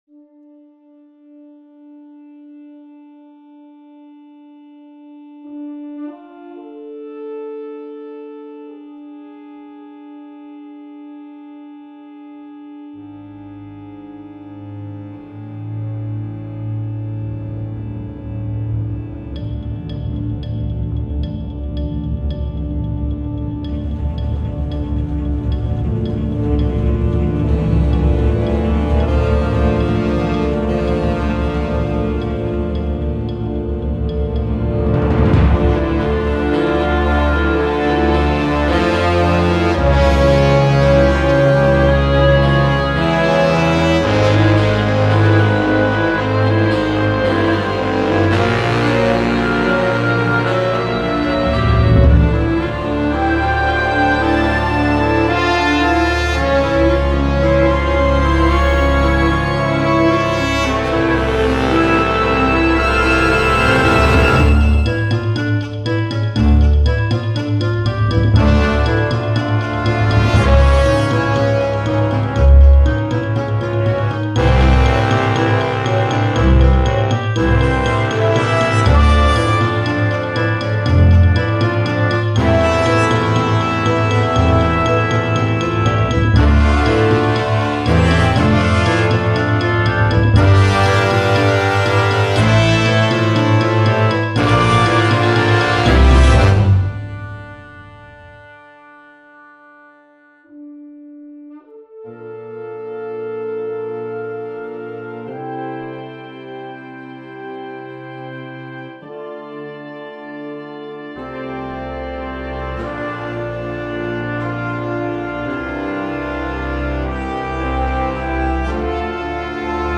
Filmmusik für Jugendblasorchester
Besetzung: Blasorchester